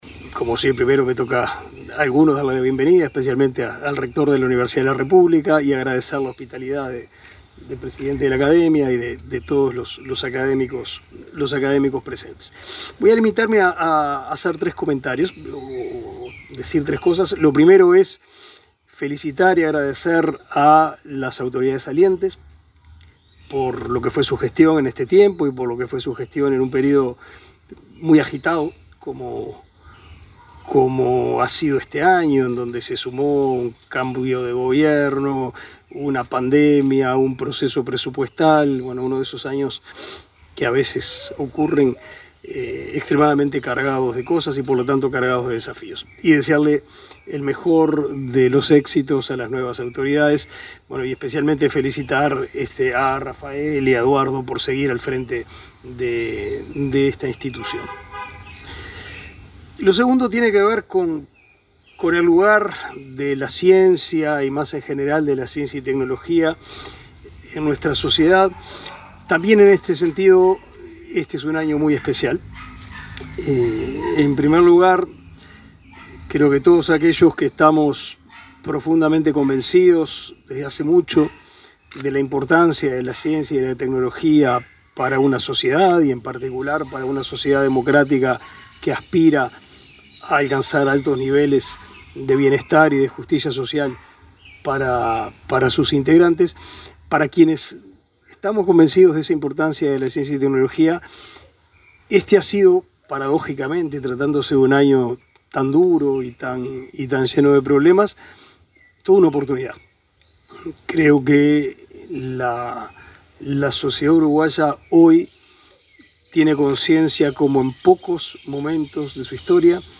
Palabras del ministro de Educación y Cultura, Pablo da Silveira
El ministro de Educación y Cultura, Pablo da Silveira, encabezó el acto de cierre anual de la Academia Nacional de Ciencias del Uruguay, el miércoles